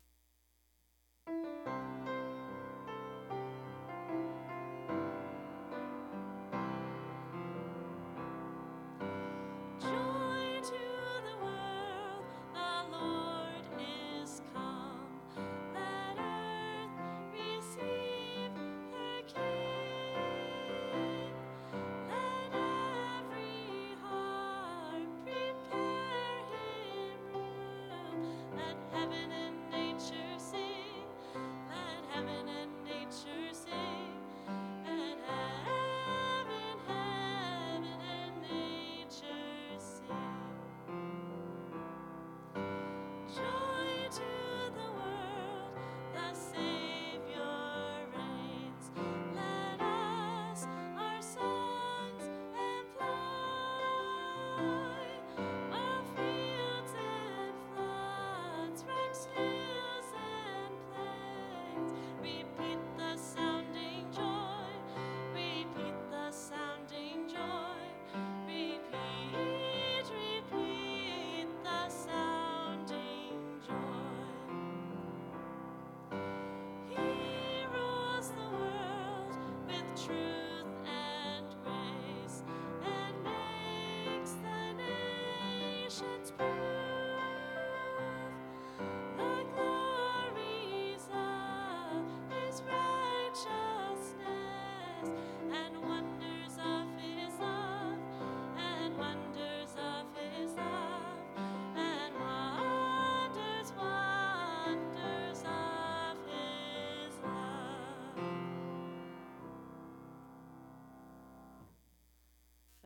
Click here for practice track